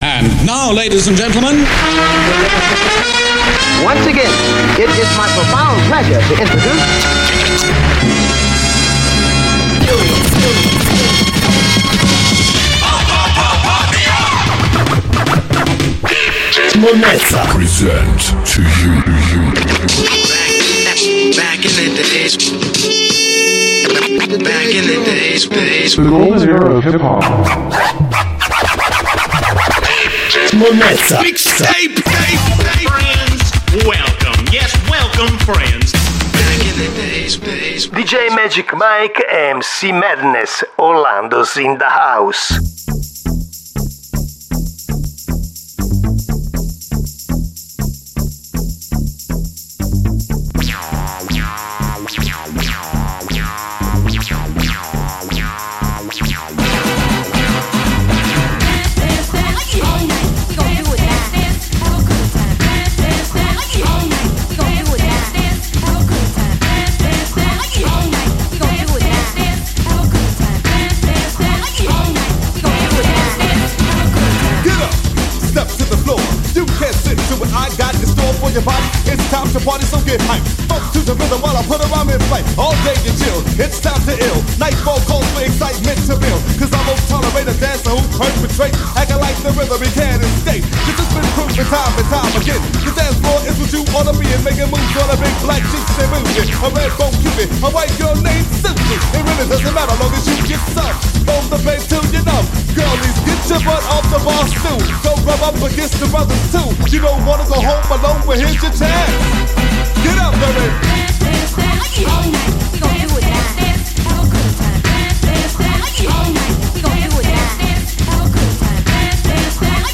Uno spoken al vetriolo su una base country western…un buon esempio di rap…prima del rap!